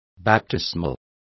Complete with pronunciation of the translation of baptismal.